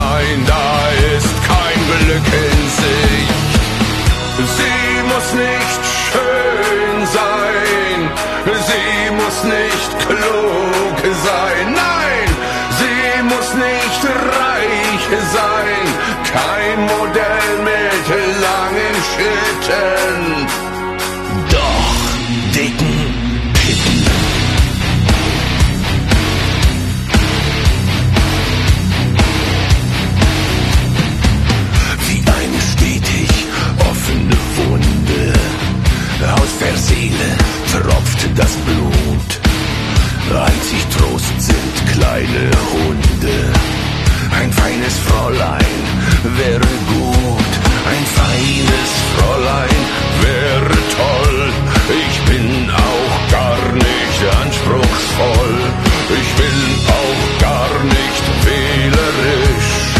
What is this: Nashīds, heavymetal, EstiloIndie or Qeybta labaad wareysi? heavymetal